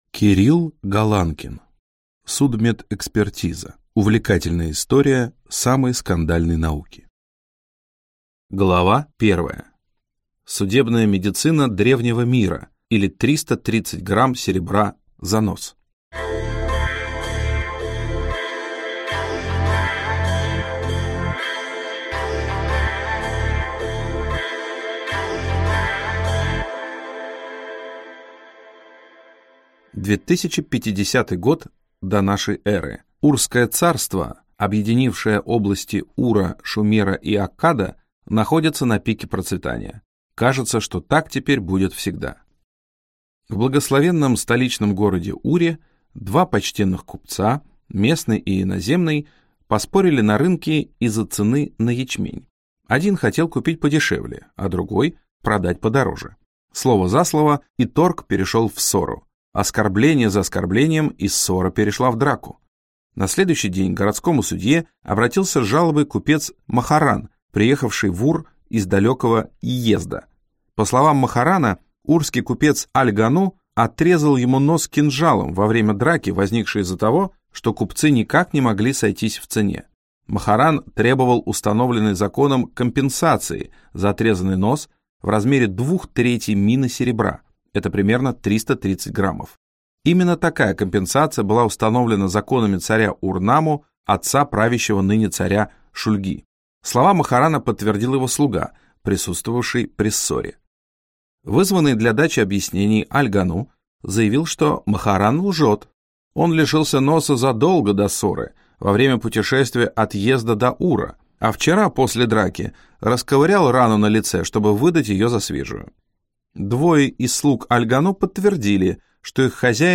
Аудиокнига Судмедэкспертиза. Увлекательная история самой скандальной науки | Библиотека аудиокниг